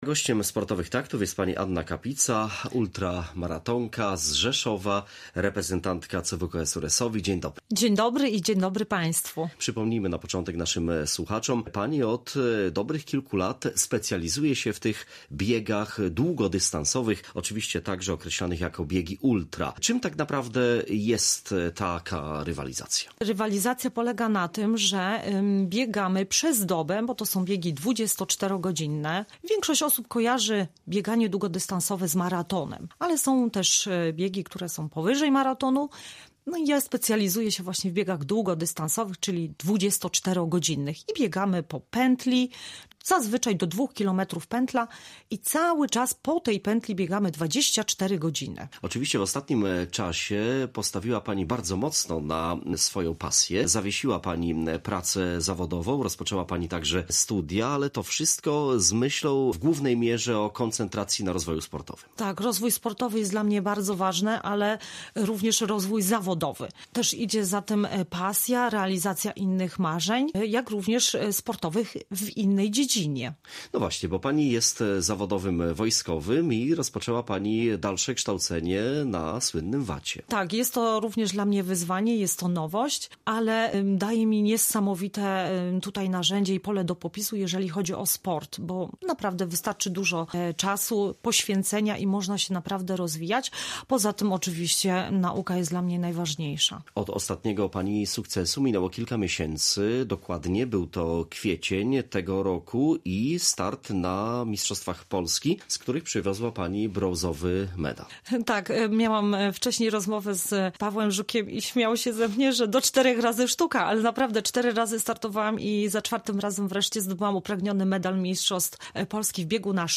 rozmawia ze swoim gościem o wyjątkowym sportowym wyzwaniu, jakim jest rywalizacja w biegu 24-godzinnym.